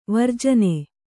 ♪ varjane